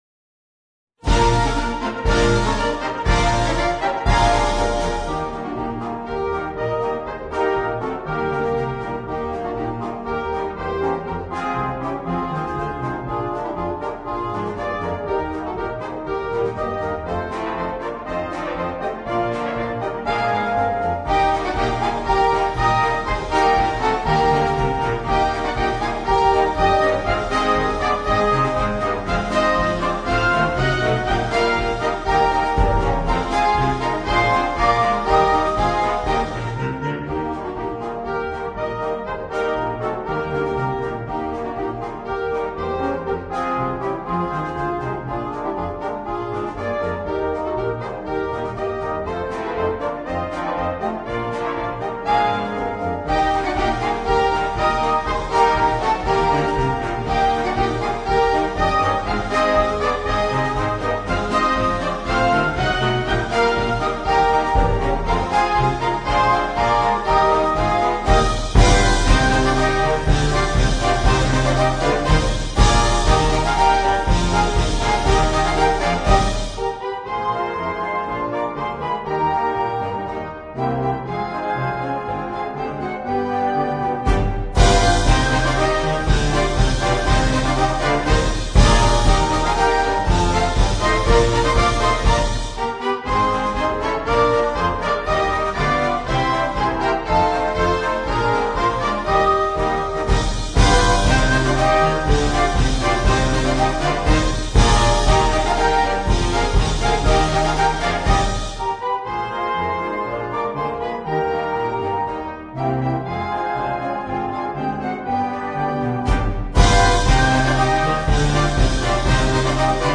Marcia brillante scaricabile anche gratuitamente DOWNLOAD